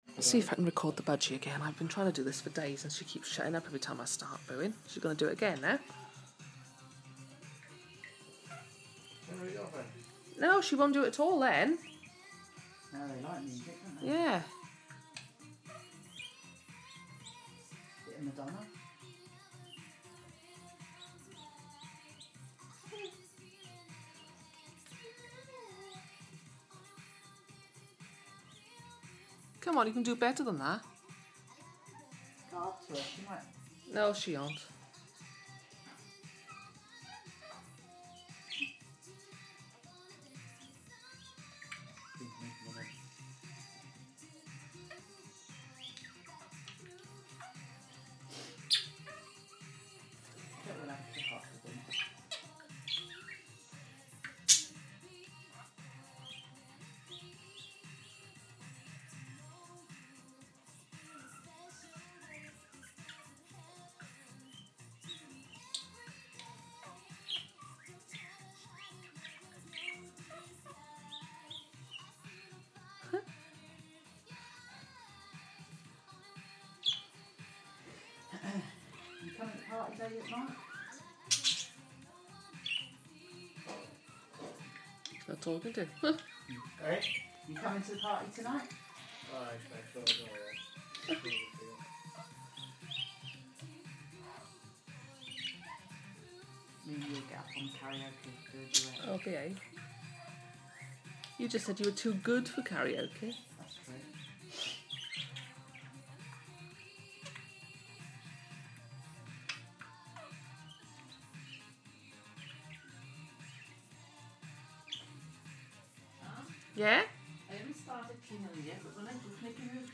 chatty bird